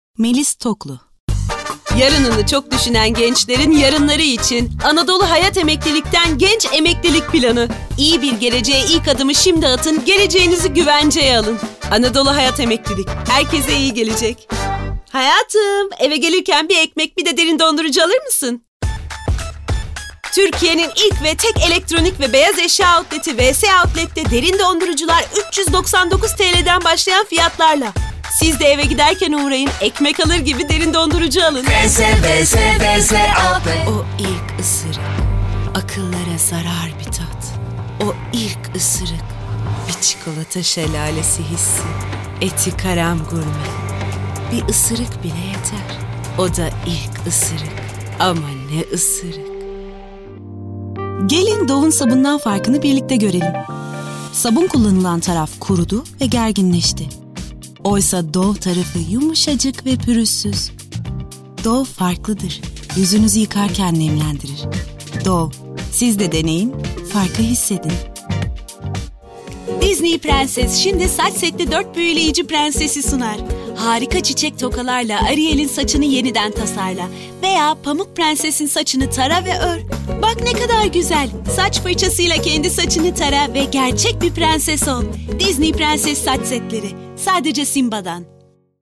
Kadın Genç Kadın
DEMO SESLERİ
Canlı, Eğlenceli, Spiker, Sakin, Güvenilir, Karakter, IVR, Seksi, Animasyon, Şefkatli, Promosyon, Sıcakkanlı, Film Sesi, Genç, Dostane,